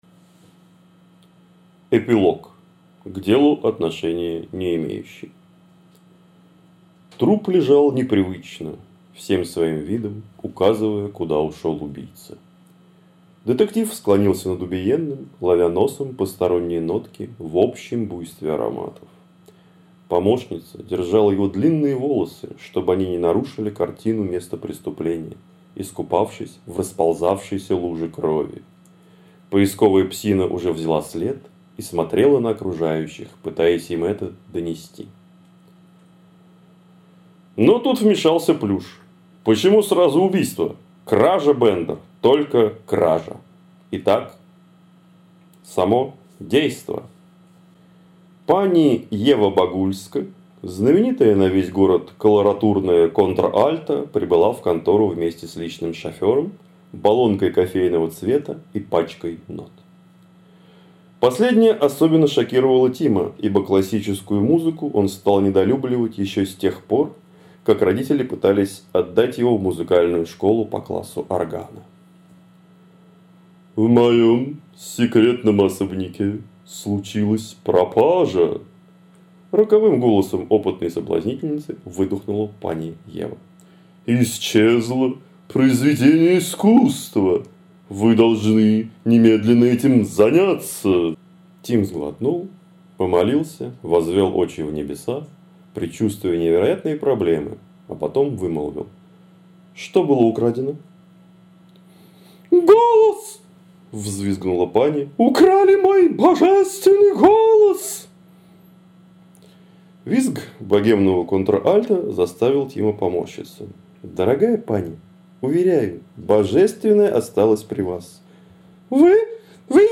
На разные голоса - здорово!
Какой диапазон, от басистости до фальцета сапраны.